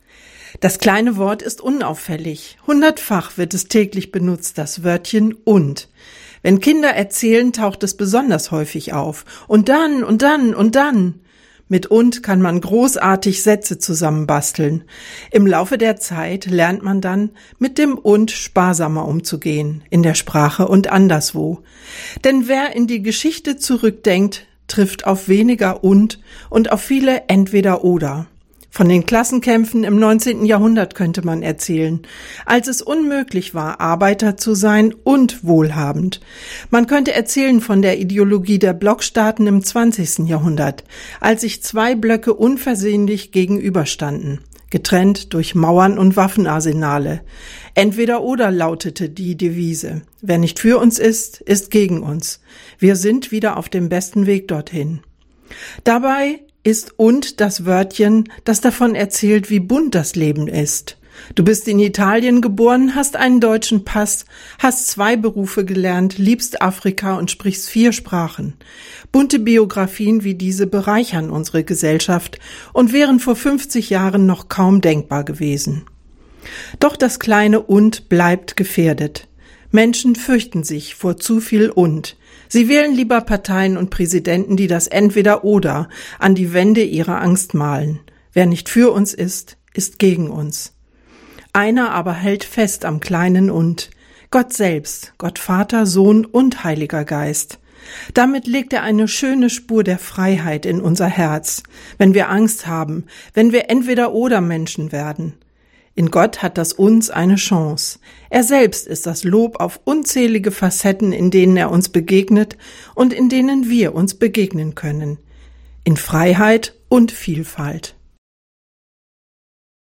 Radioandacht vom 7. September